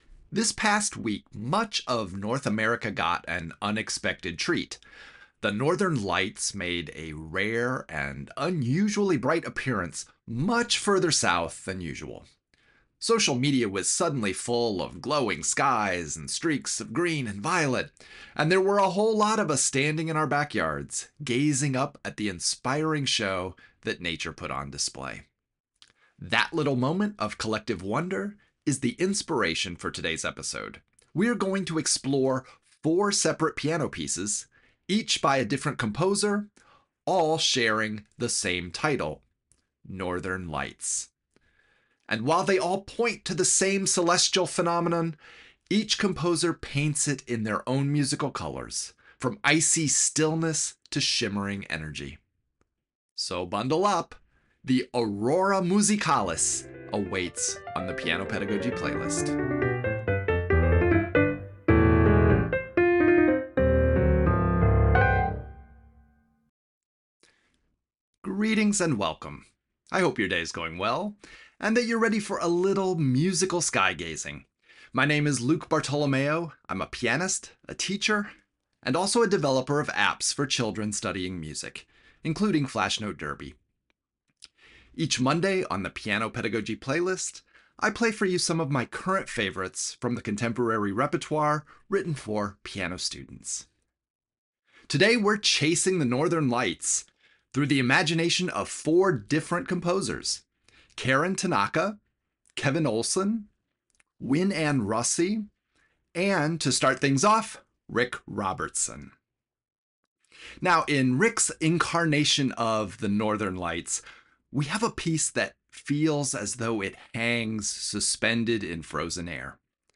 From quiet, timeless stillness to full-blown toccata energy, these pieces give students a chance to experience a wide spectrum of color and character—all sparked by the same celestial phenomenon.